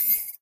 Type machine
描述：Type writing machine being typed: click and punchy sound
标签： click Typemaching Type Line press Typing Slow Single push Break Click Fast clicky Button Skip OWI spacebar Machine Antique
声道立体声